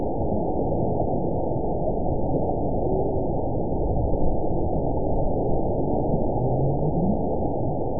event 921774 date 12/18/24 time 23:22:20 GMT (6 months ago) score 9.40 location TSS-AB02 detected by nrw target species NRW annotations +NRW Spectrogram: Frequency (kHz) vs. Time (s) audio not available .wav